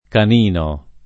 [ kan & no ] agg. («di cane»)